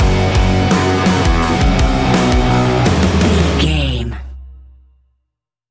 Ionian/Major
F♯
heavy rock
heavy metal
instrumentals